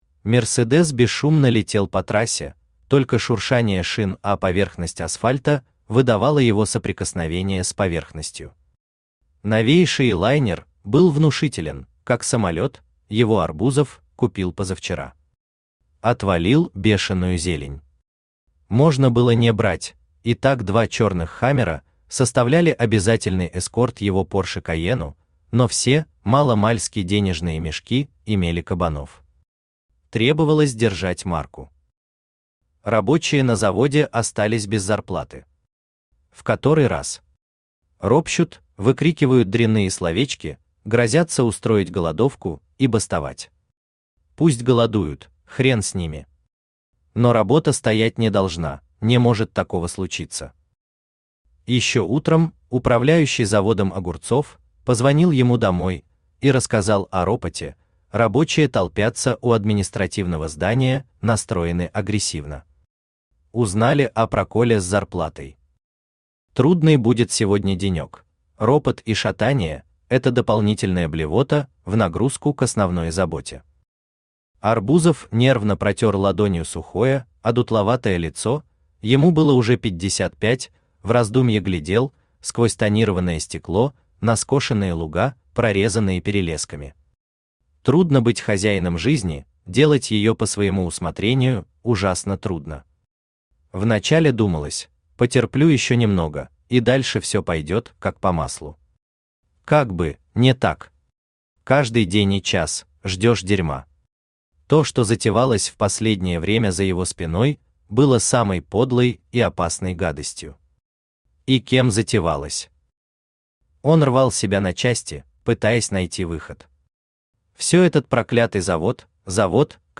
Аудиокнига Извращенность | Библиотека аудиокниг
Aудиокнига Извращенность Автор Алексей Николаевич Наст Читает аудиокнигу Авточтец ЛитРес.